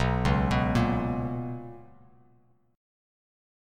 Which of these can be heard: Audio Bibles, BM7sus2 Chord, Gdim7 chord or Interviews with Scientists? BM7sus2 Chord